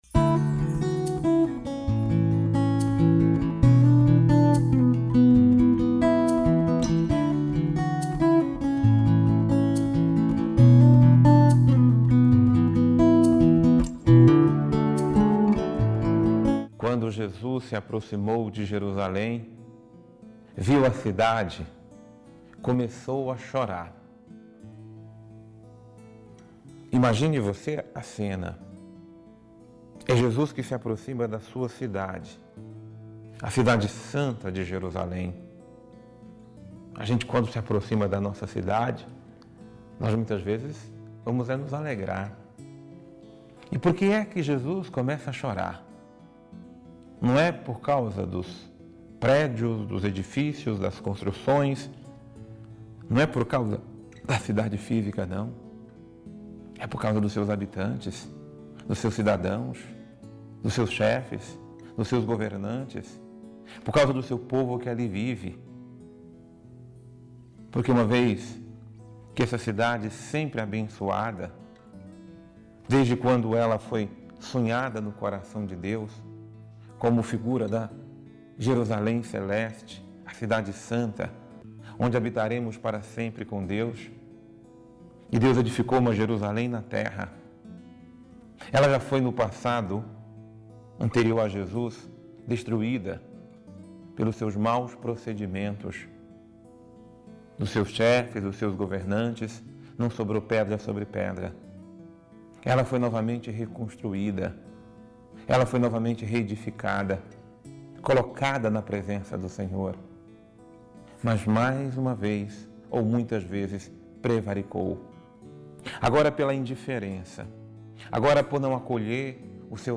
Homilia | Nossas cidades precisam da presença de Jesus